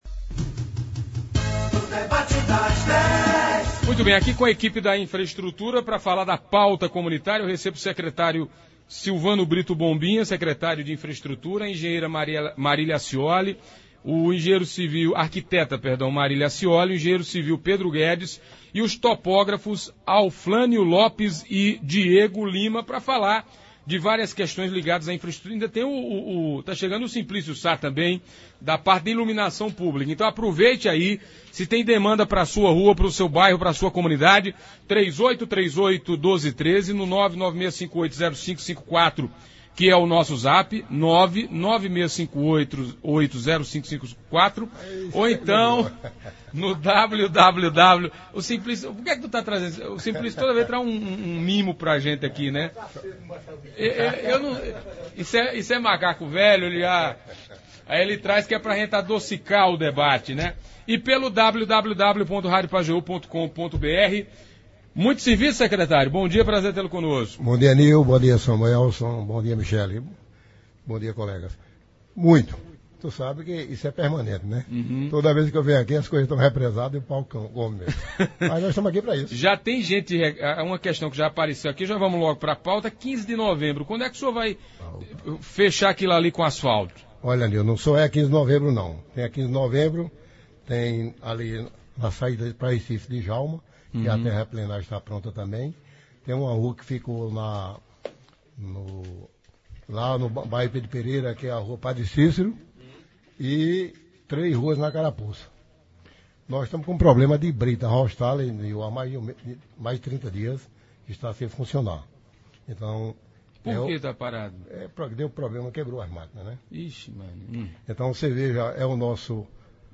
Os ouvintes e internautas participaram informando problemas, questionando e cobrando ações.